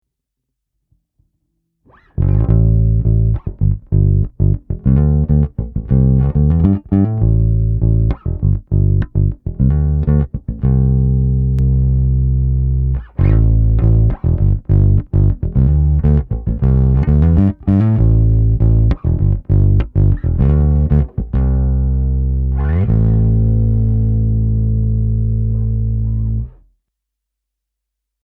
Ich hab es jetzt mal an 12V angeschlossen (Cioks DC7). Anbei ein kleines Sample (zuerst Bass Jive im Bypass, dann Bass Jive aktiviert).
Low End: Mitte Voicing: Mitte Mode: Links Gain: auf "0" Anhänge IMG_8959.jpeg 158,4 KB · Aufrufe: 46 Bass-Jive.mp3 666,4 KB